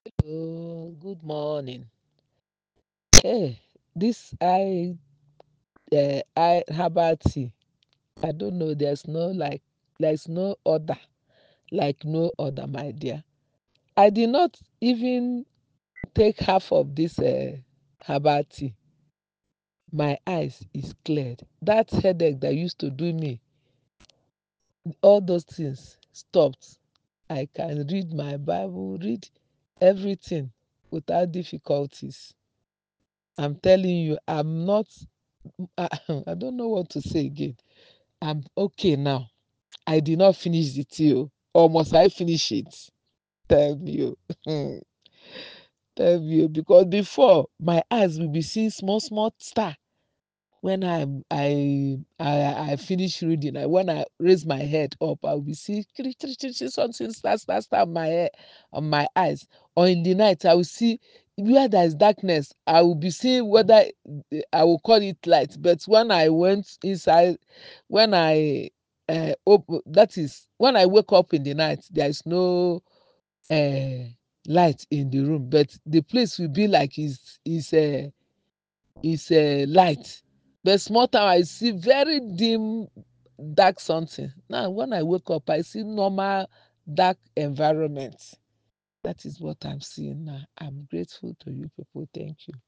Verified Customer
Testimonial 6